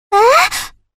小女孩吃惊唉一声音效_人物音效音效配乐_免费素材下载_提案神器
小女孩吃惊唉一声音效免费音频素材下载